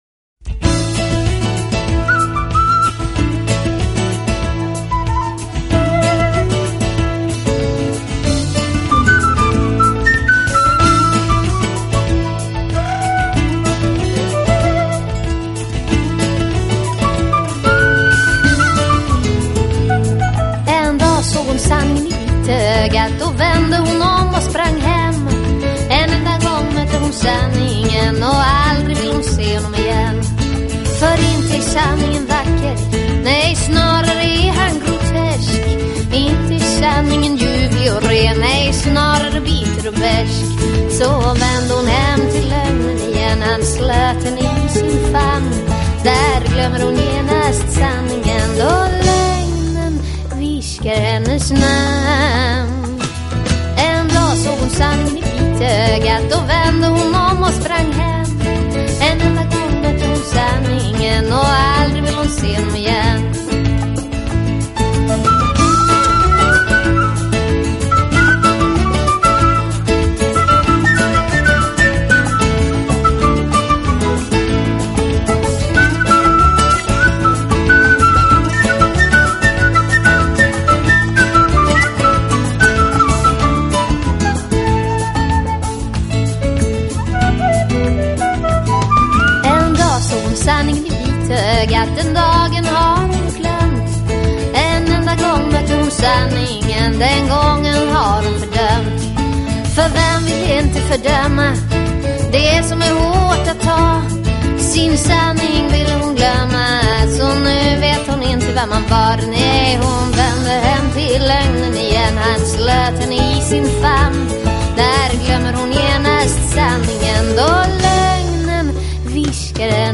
她童稚而沙哑声音习惯在夜晚覆盖整个欧陆。
Vocal Jazz、Trad Jazz